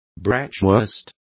Complete with pronunciation of the translation of bratwurst.